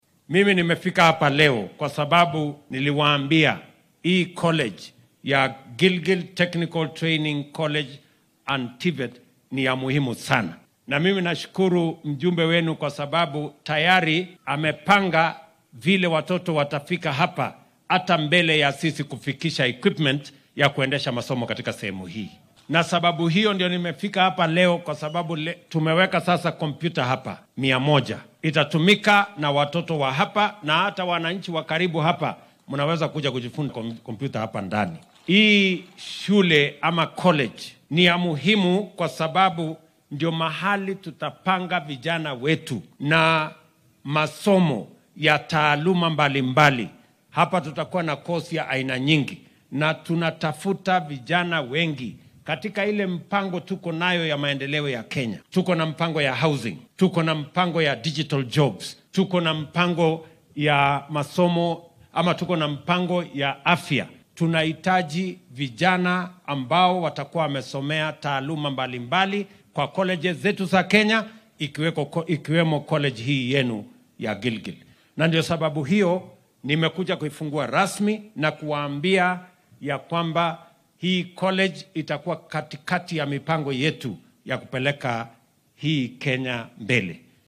Arrimahan ayuu ka hadlay xilli uu deegaanka Gilgil ee ismaamulka Nakuru si rasmiga ah uga furayay machadka lagu barto farsamada.